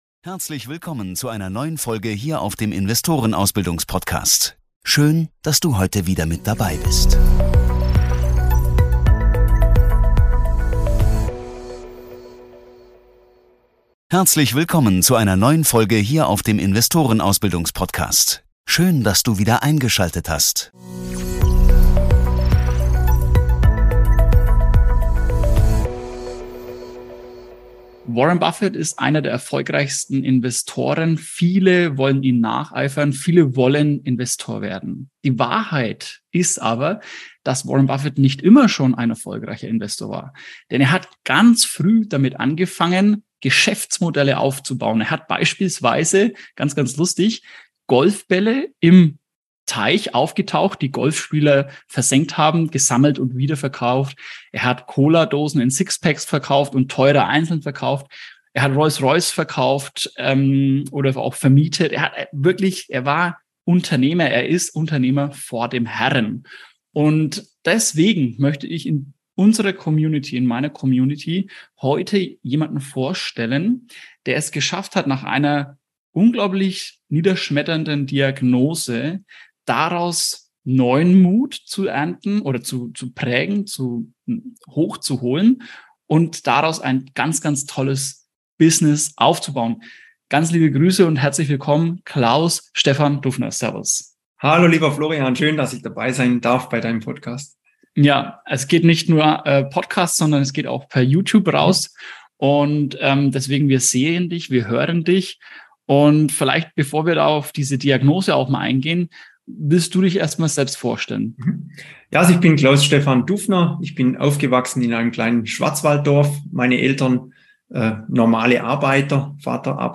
#35 Von der Negativ-Diagnose zum Multiunternehmer // Interview